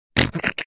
snort.ogg